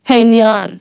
synthetic-wakewords
ovos-tts-plugin-deepponies_Billie Eilish_en.wav